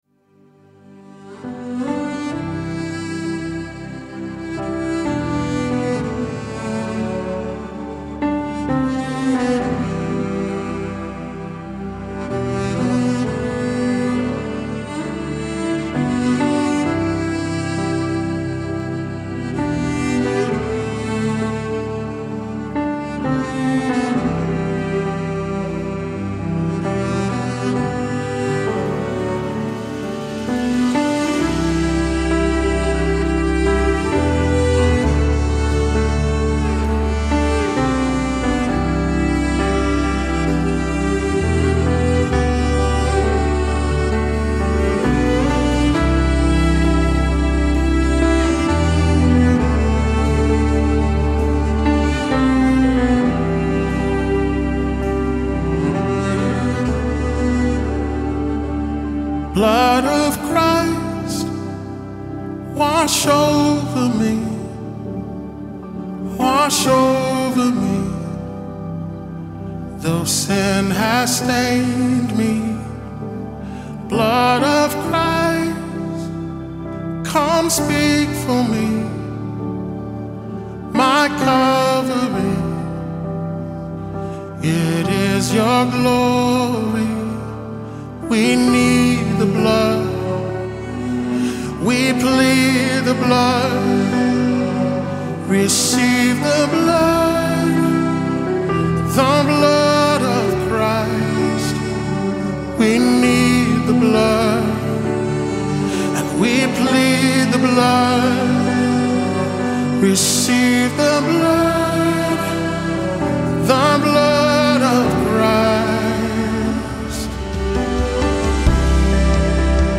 BPM: 66